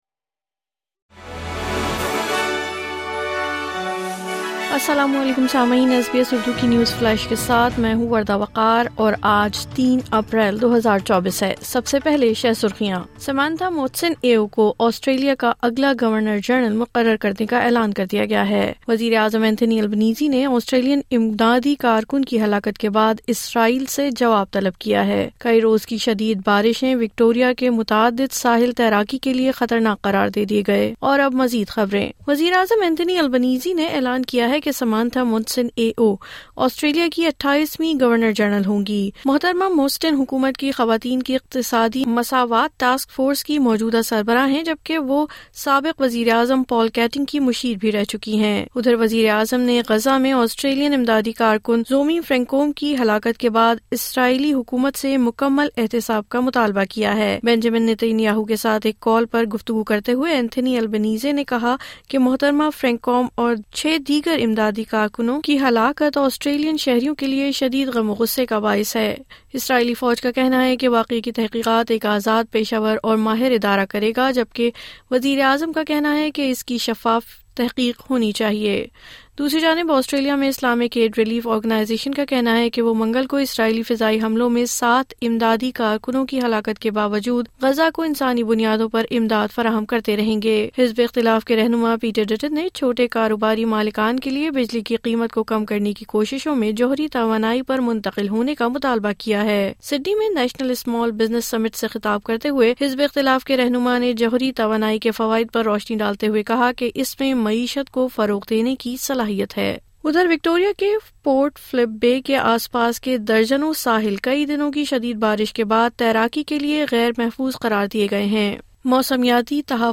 نیوز فلیش:03 اپریل 2024: غزہ میں امدادی کارکن کی ہلاکت پر وزیر اعظم کا شفاف تحقیق کا مطالبہ